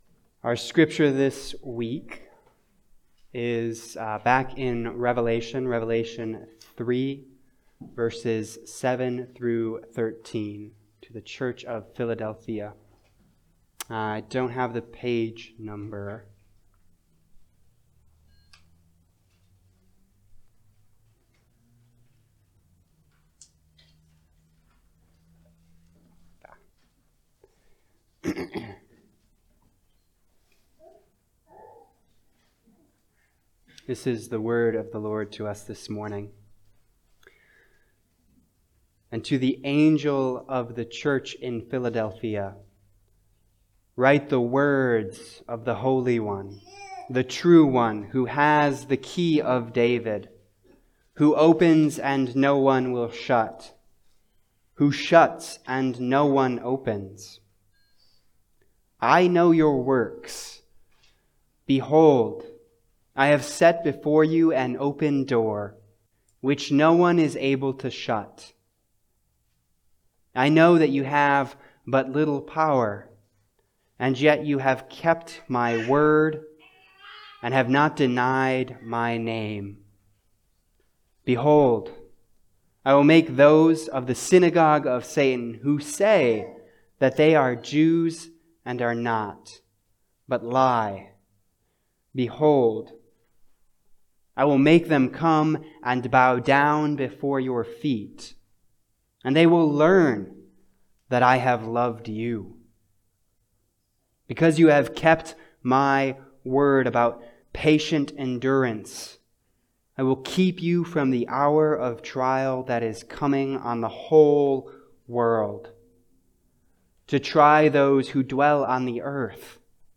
Passage: Revelation 3:7-13 Service Type: Sunday Service